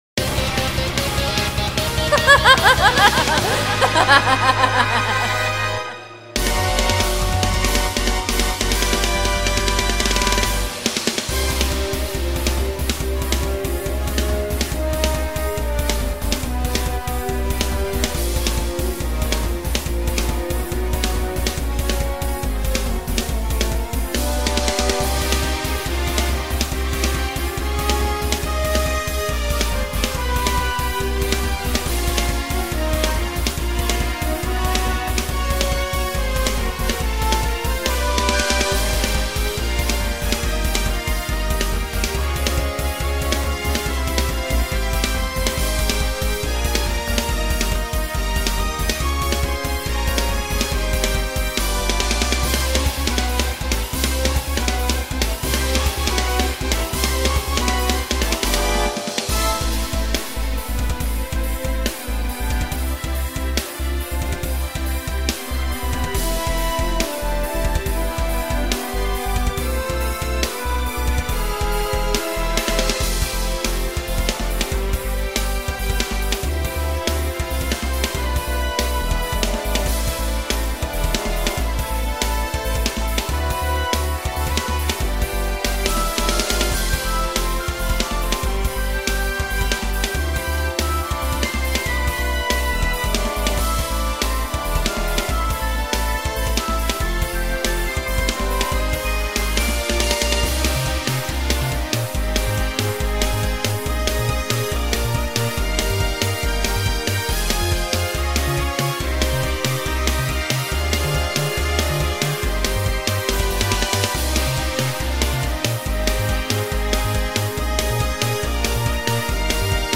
boss theme